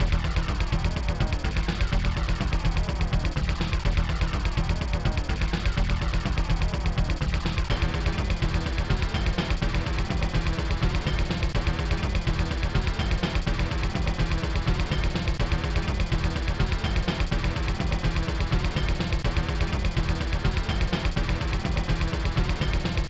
mod (ProTracker MOD (6CHN))